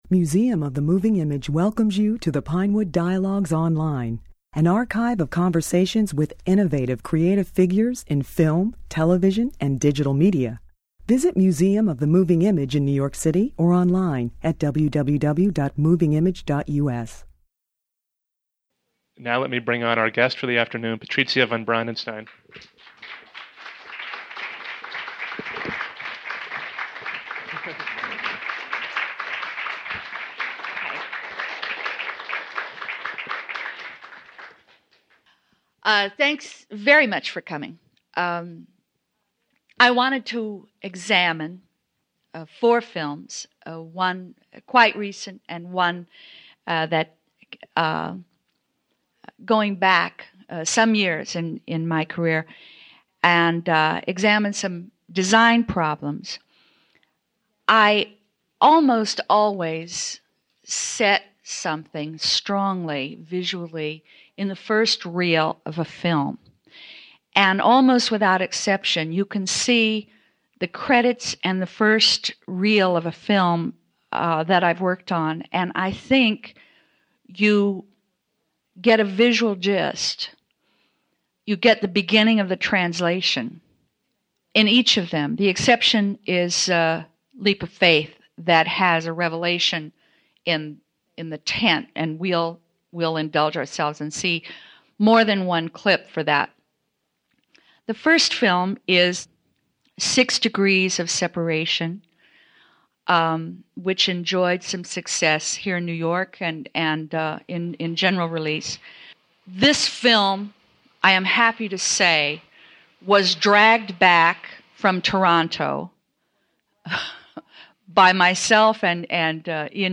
In this presentation, von Brandenstein leads the audience through sequences from her work, and lucidly defines the art of production design.